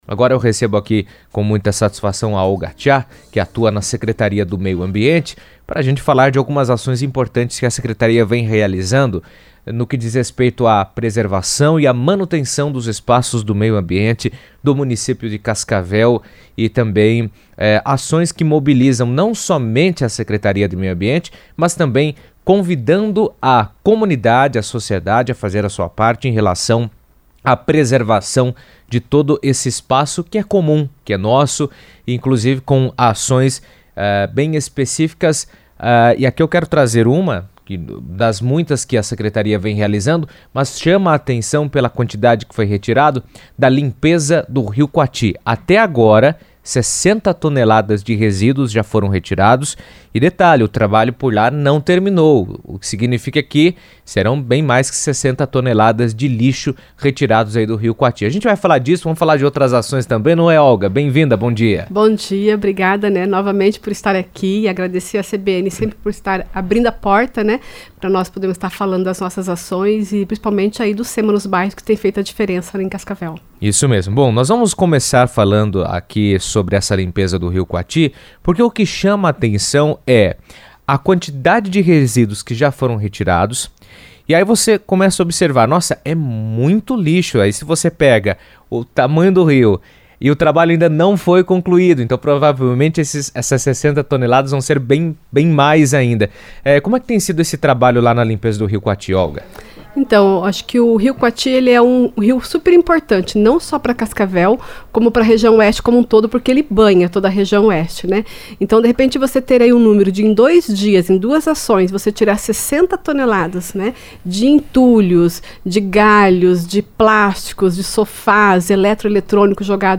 O programa Sema nos Bairros já retirou mais de 60 toneladas de lixo acumuladas no Rio Quati, reforçando ações de limpeza e preservação ambiental na região. Em entrevista à CBN